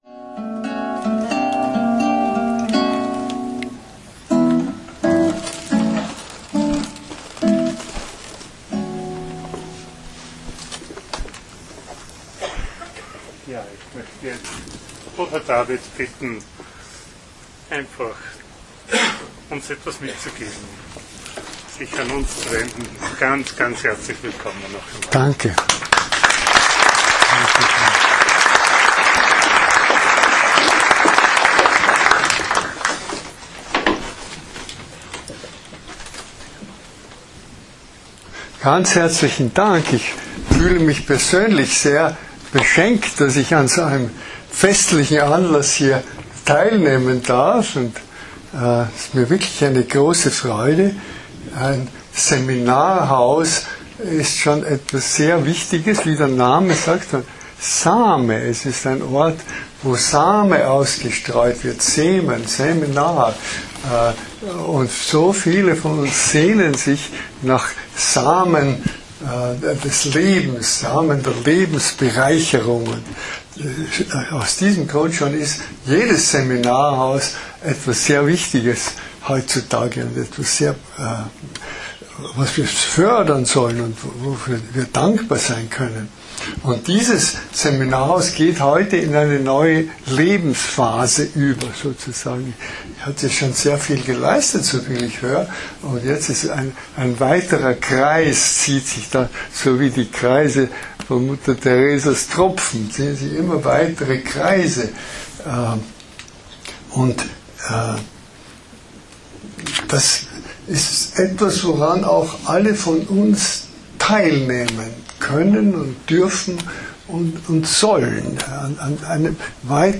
Zum Nachhören der Festvortrag von Bruder David Steindl-Rast im Rahmen unserer Erweiterungs-Fest-Tage für den Zubau des Bildungshauses.